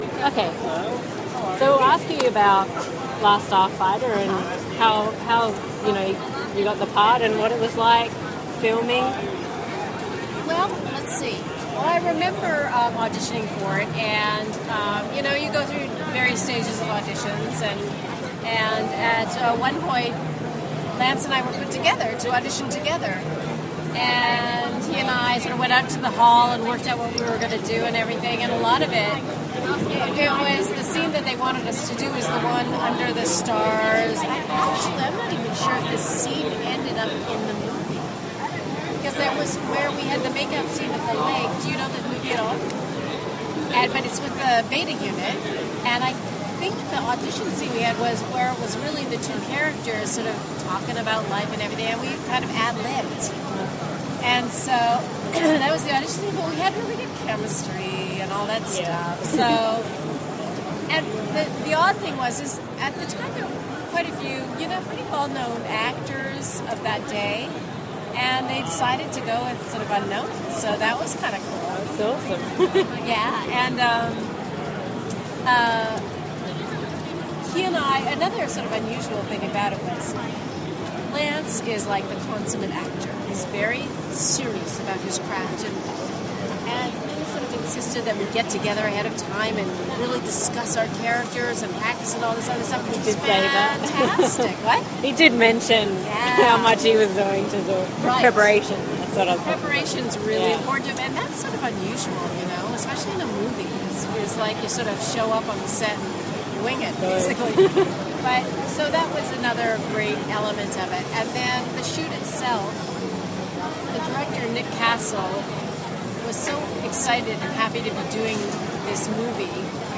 Armageddon Expo ’11 – Interview with Catherine Mary Stewart (The Last Starfighter)
Interview with Catherine Mary Stewart
armageddoninterviewwithcatherinemarystewart.mp3